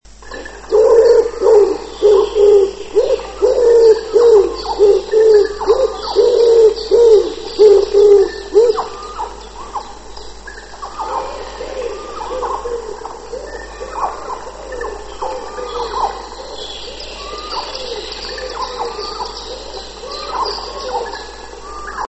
Grzywacz - Columba palumbus
grzywacze.mp3